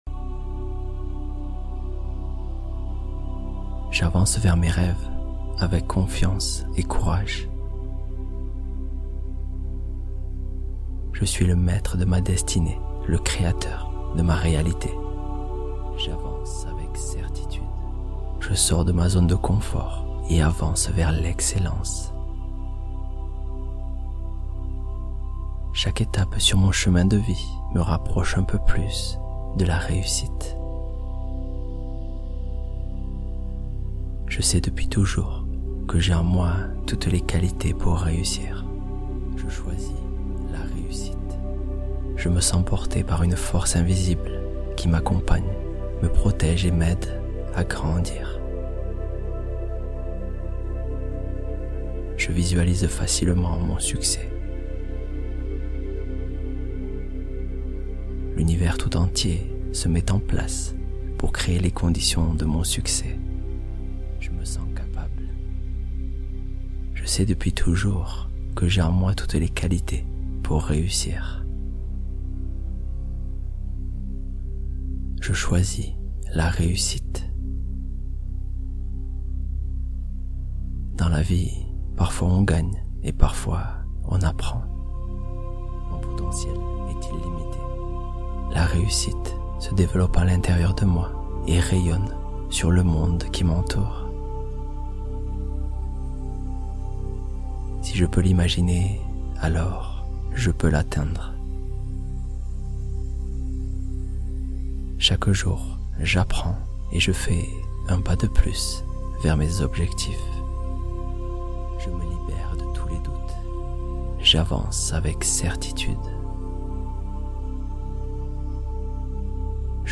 Lâcher-prise profond — Scan corporel guidé